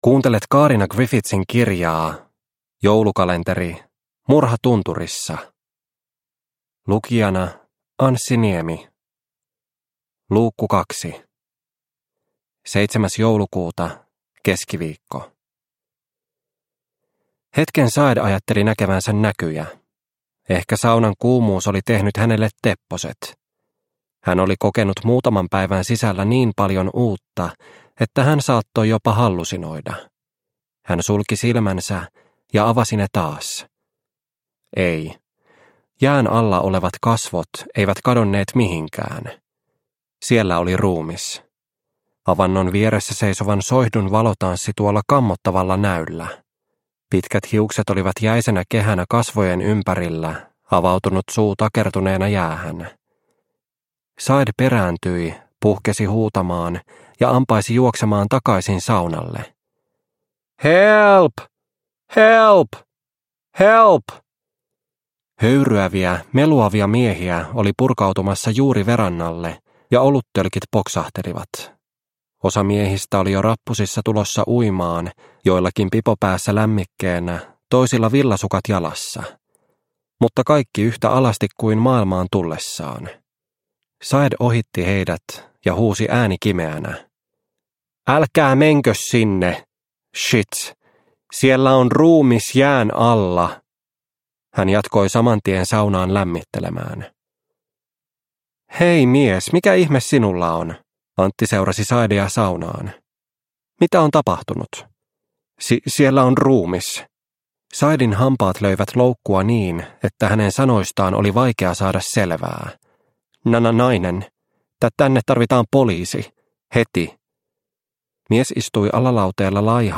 Murha tunturissa - Osa 2 – Ljudbok – Laddas ner